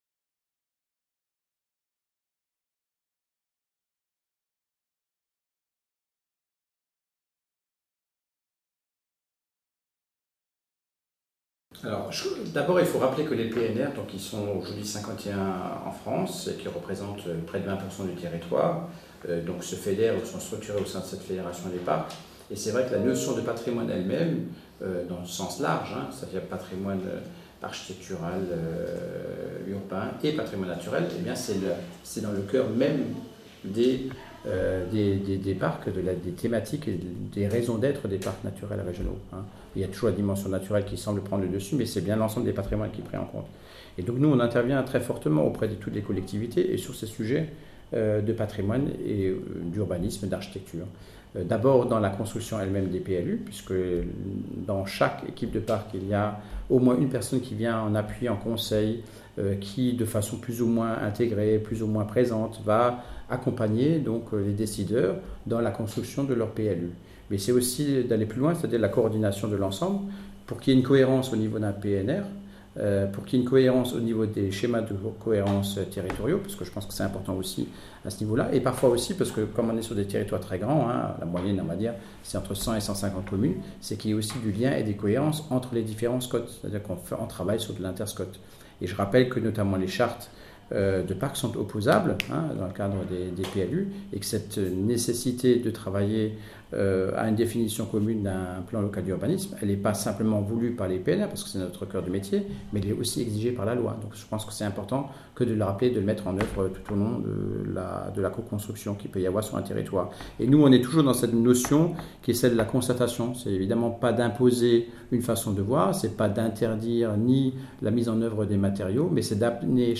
Entretien avec Michaël WEBER Président de la Fédération des Parcs naturels régionaux de France et Président du PNR des Vosges du Nord. Entretien réalisé à Paris le 7 septembre 2016. À noter : Cet entretien est un matériau de recherche brut, qui a nourri les réflexions des chercheurs dans le cadre du projet PLU PATRIMONIAL.